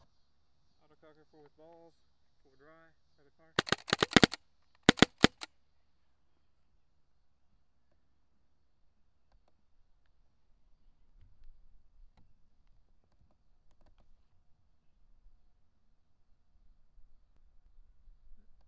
autococker_raw_notclipped_01.wav